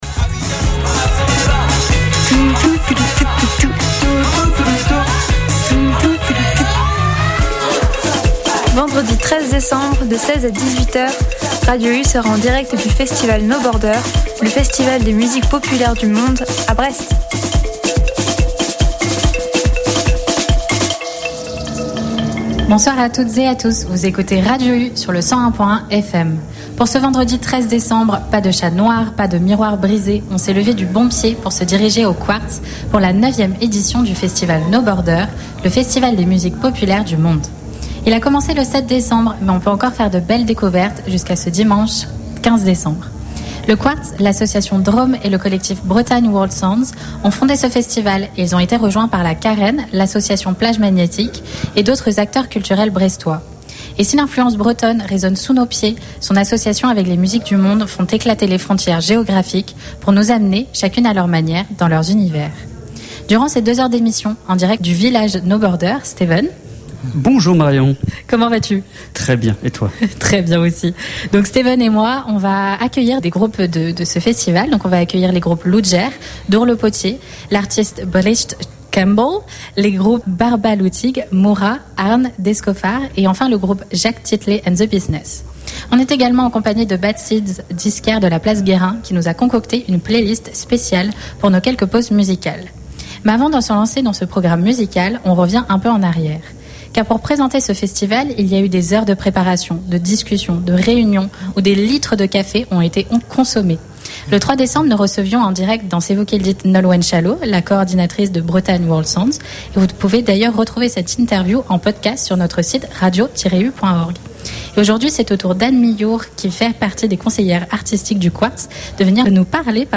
Le vendredi 13 décembre dernier, Radio U était en direct du Quartz pour la 9ème édition du festival No Border.